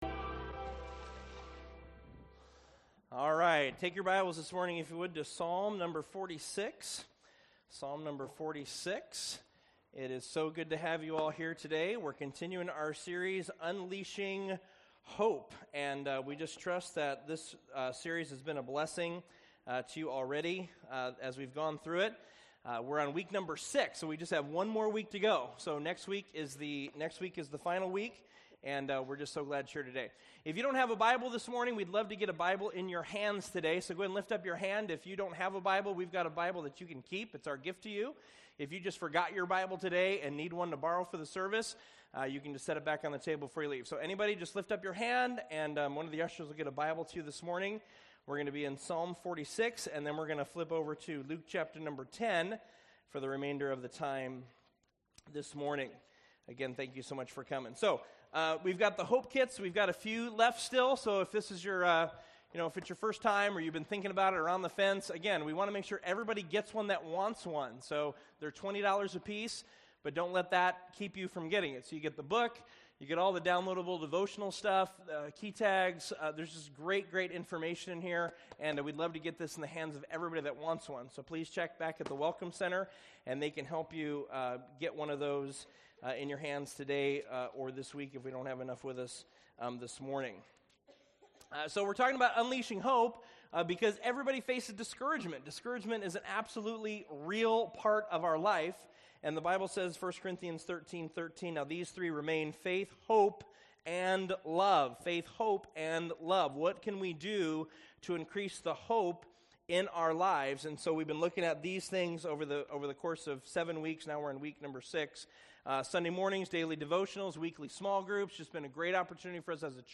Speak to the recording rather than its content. Service Type: Weekend Services